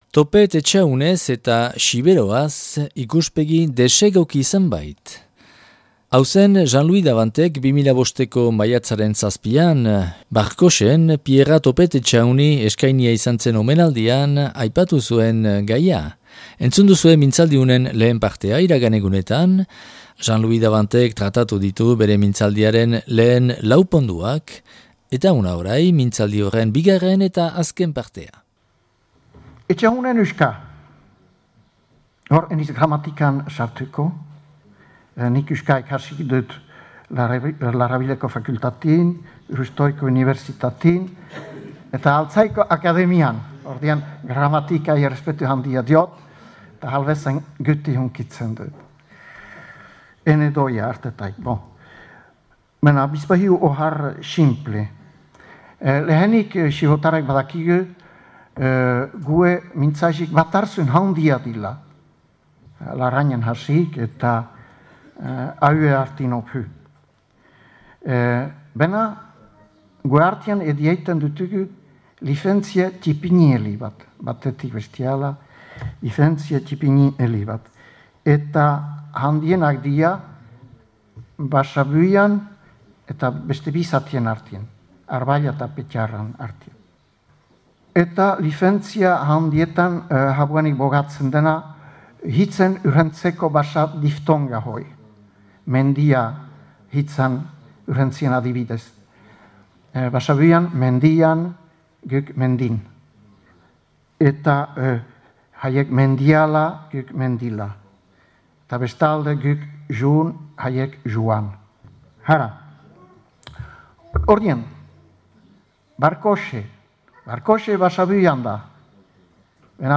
(Grabatua Barkoxen 2005. Maiatzaren 7an).